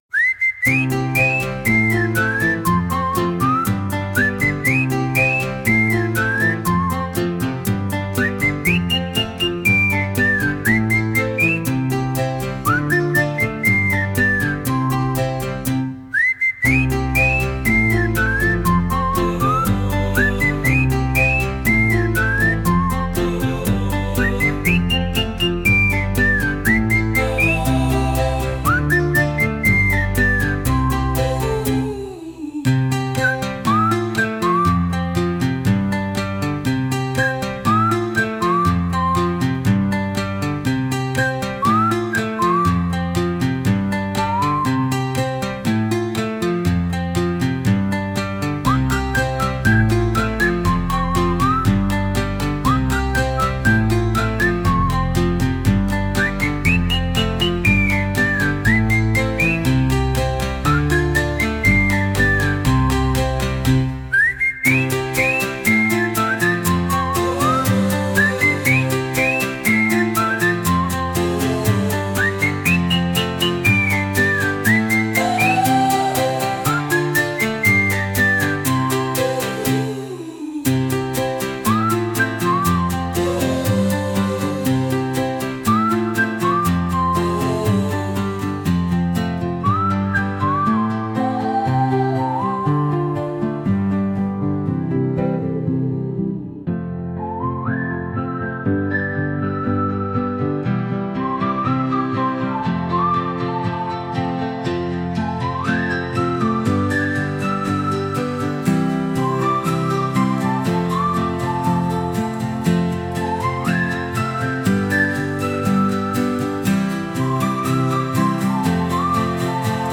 Sung by Suno
Sheepishly_Whistling_(Cover)_mp3.mp3